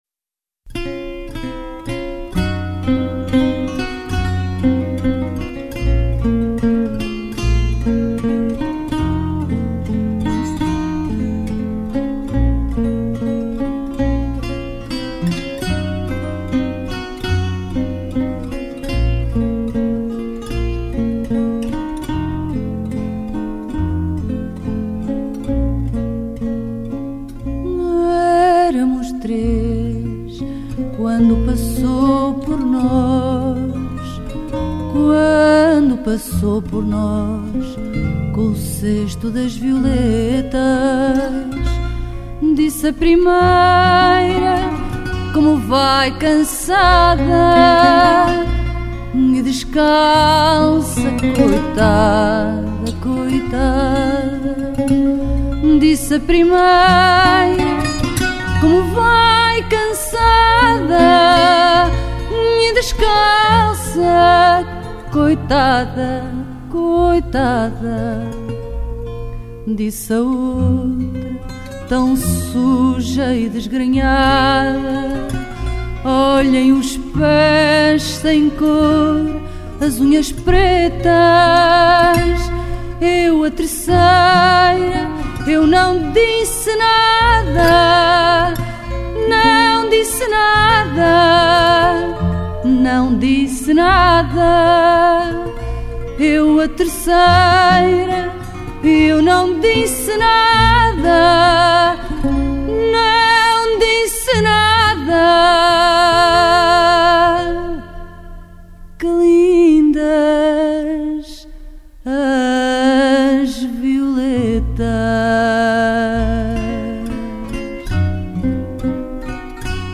Genre: Fado, Folk